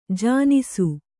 ♪ jānisu